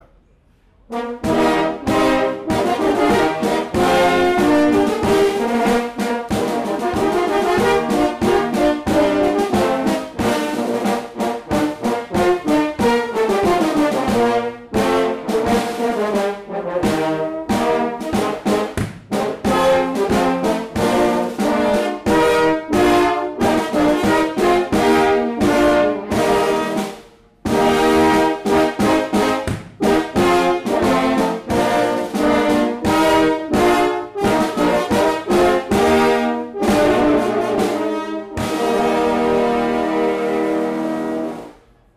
Unterhaltsames Sommerkonzert
In Einsiedeln präsentierte die Schwyzer Horngruppe zusammen mit den Junior Horns und den Cherry Stones ein abwechslungsreiches Open-Air-Konzert.
Rockige Hornklänge mit Schlagzeug-Unterstützung
Rule Britannia (live)